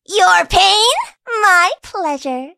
willow_kill_vo_07.ogg